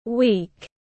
Tuần tiếng anh gọi là week, phiên âm tiếng anh đọc là /wiːk/
Week /wiːk/